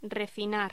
Locución: Refinar
voz cocina hostelería locución refinar